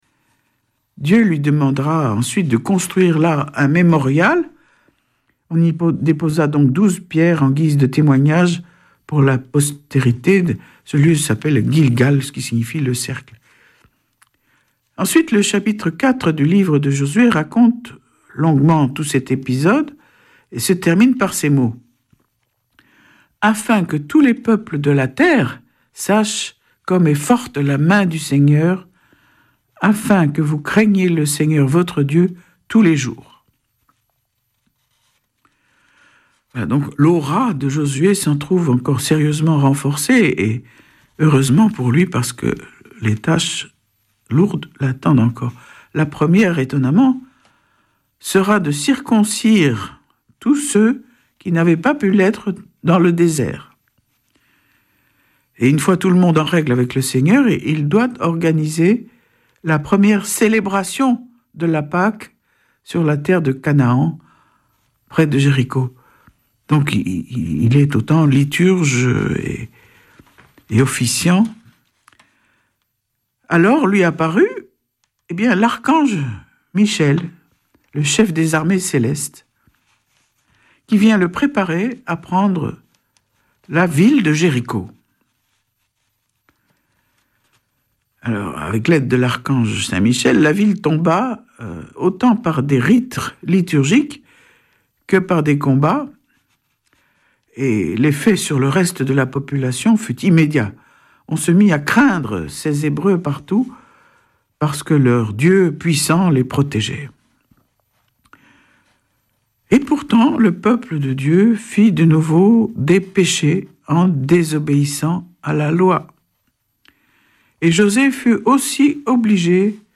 Vêpres de Saint Sernin du 03 août
Une émission présentée par Schola Saint Sernin Chanteurs